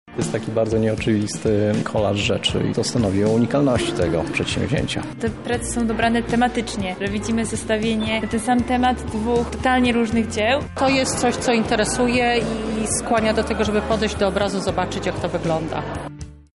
Uczestnicy wernisażu podziwiali eksponaty w różnych miejscach zamku, nawet w baszcie i na dziedzińcu. Zdradzili nam, co sądzą o takim połączeniu.